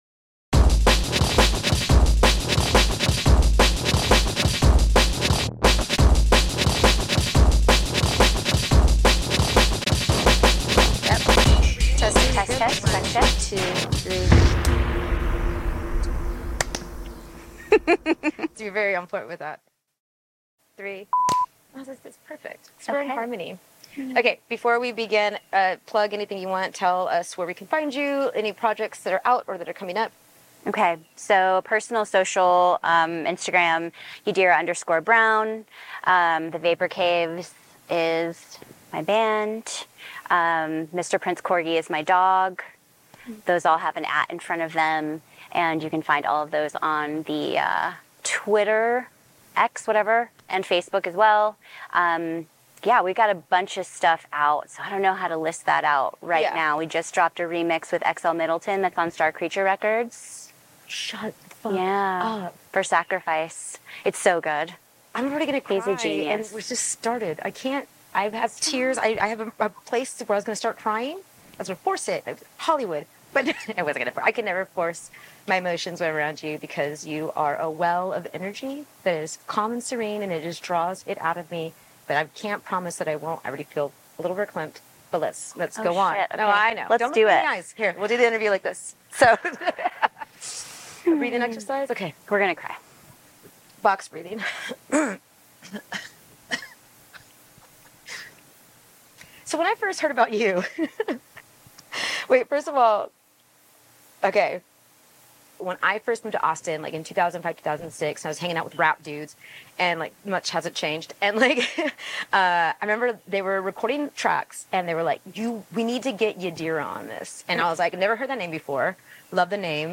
Intimate conversations with with music producers, Djs, and any wise soul that supports sub-culture. Based in Austin, TX and created by three long time friends, the Beats Within pod-project is a passionate dedication for preserving Austin's famously admired music culture and promoting the art and history of underground communities.